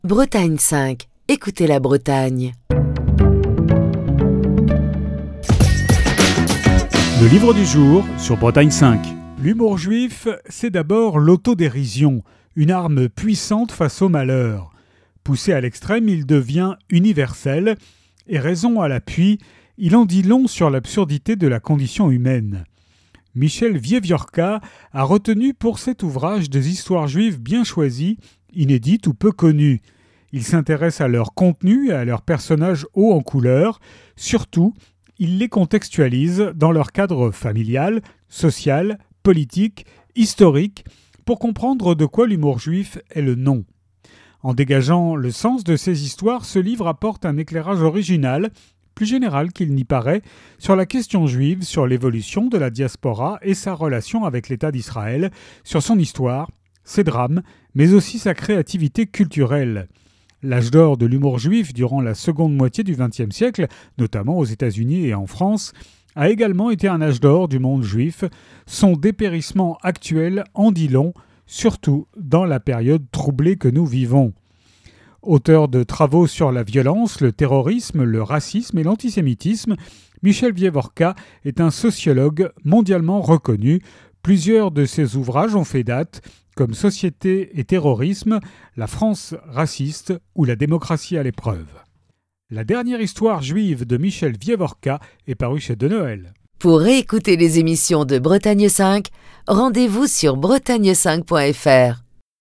Chronique du 7 novembre 2023.